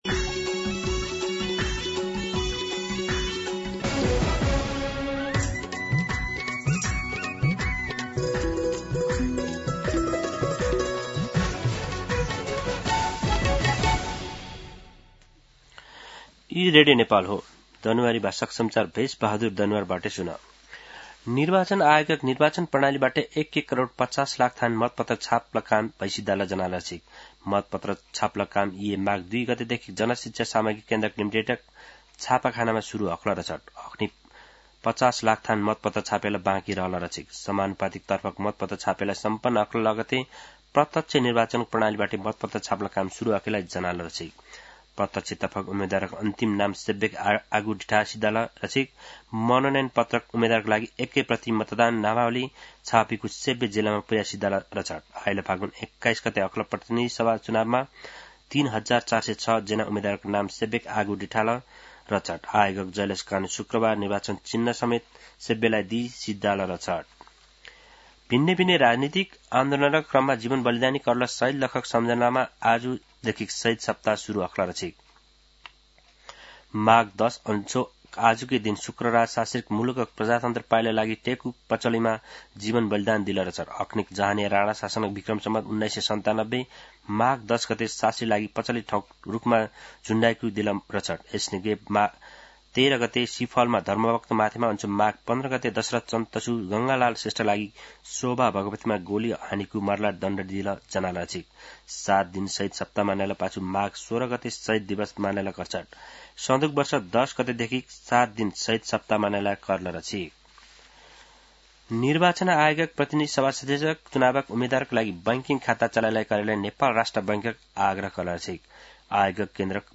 दनुवार भाषामा समाचार : १० माघ , २०८२